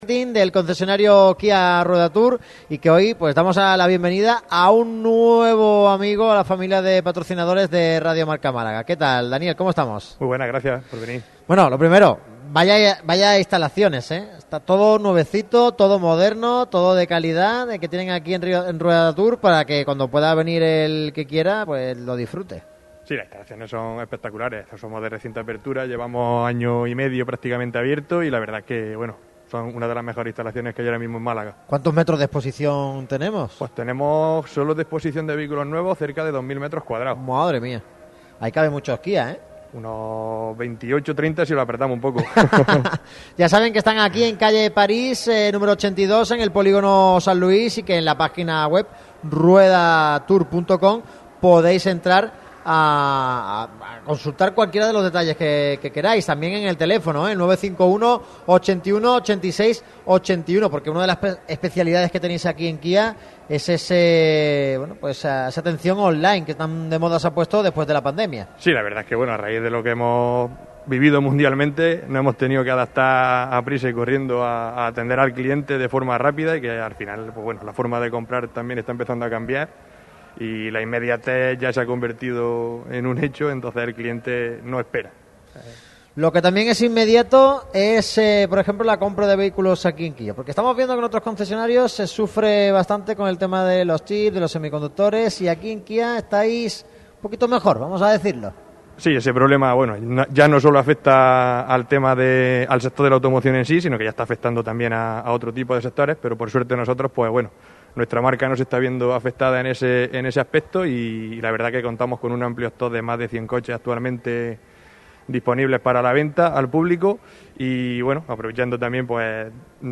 Así son las instalaciones de Ruedatur KIA en las que Radio MARCA Málaga ha hecho su programa en el día de hoy.